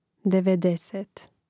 devedèsēt devedeset